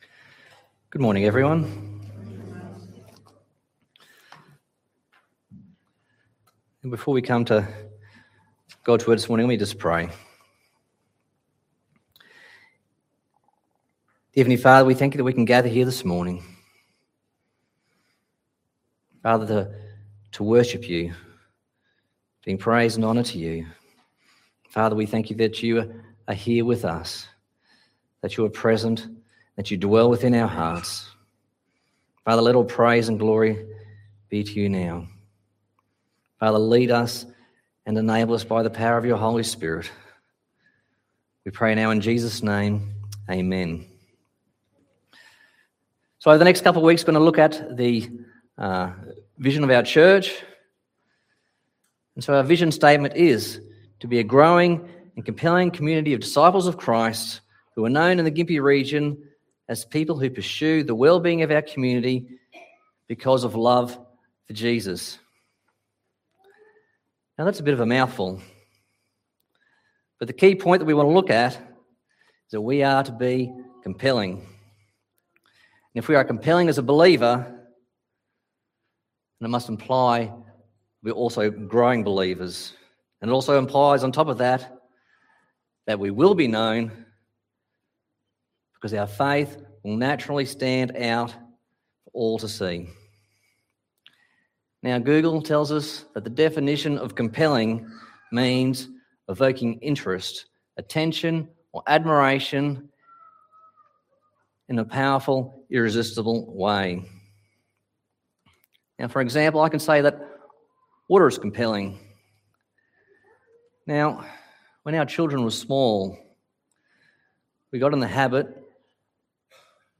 Matthew 5:13-16 Service Type: Sunday Morning « Leaves Without Fruit Church Vision